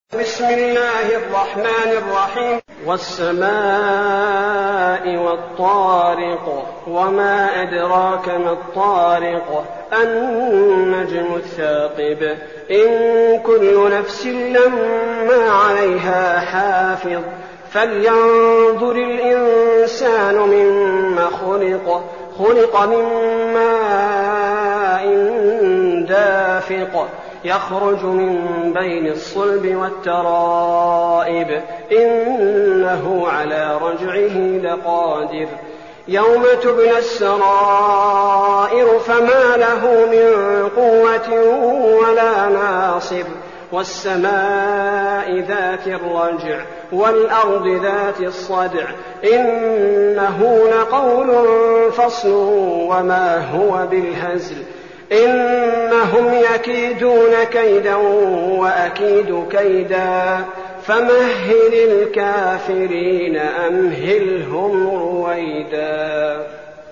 المكان: المسجد النبوي الشيخ: فضيلة الشيخ عبدالباري الثبيتي فضيلة الشيخ عبدالباري الثبيتي الطارق